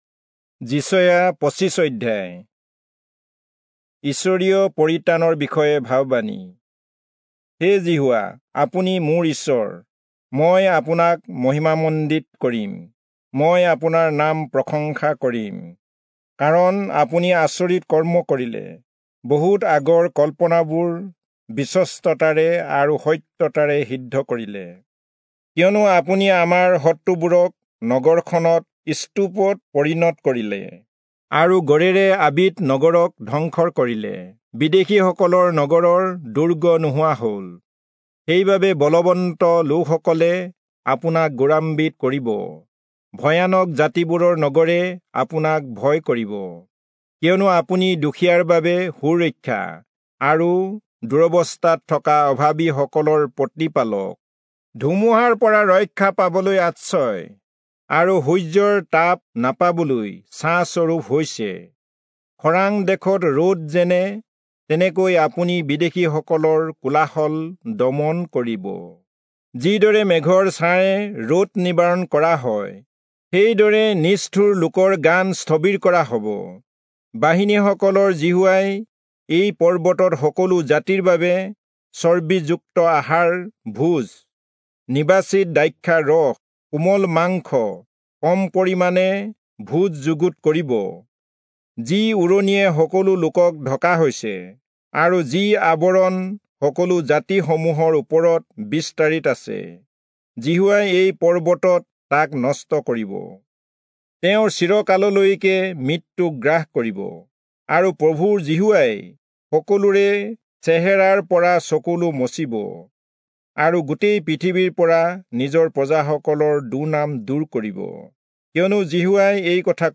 Assamese Audio Bible - Isaiah 46 in Mrv bible version